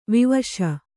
♪ vivaśa